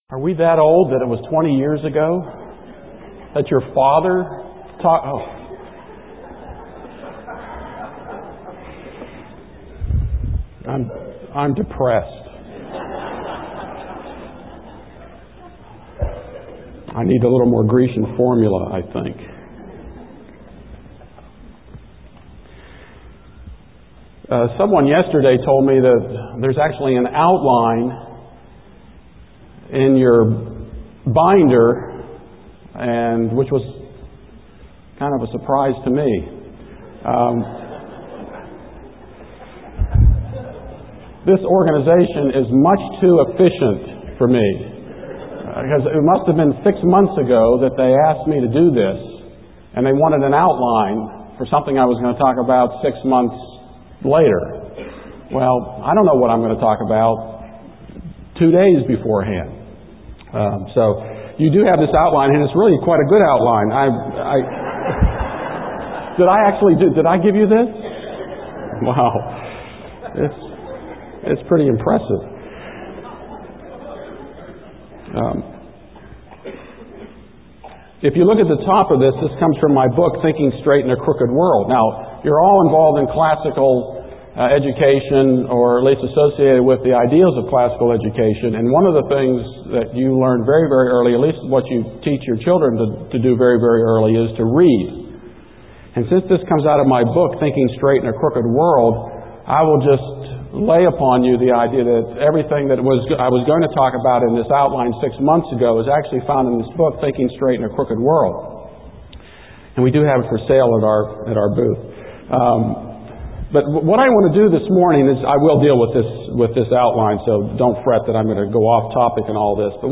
2005 Plenary Talk, 0:41:54, All Grade Levels, Culture & Faith